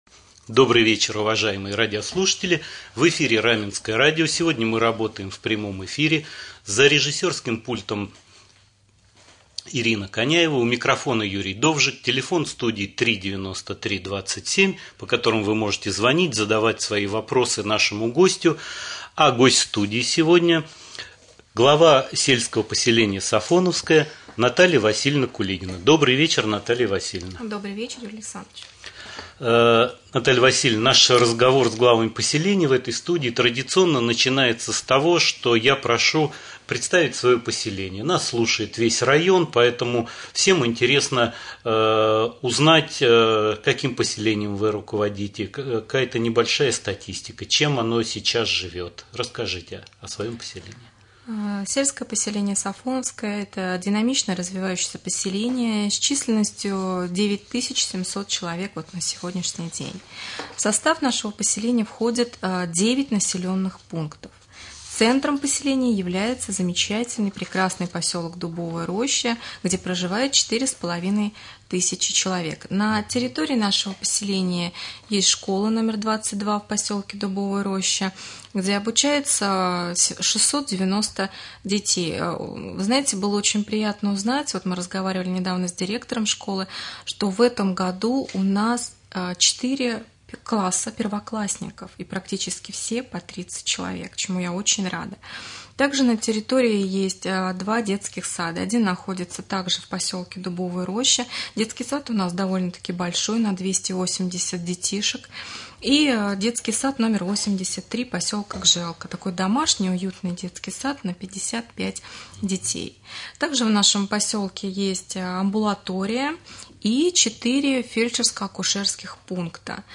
Прямой эфир с главой с/п Сафоновское Кулигиной Натальей Васильевной.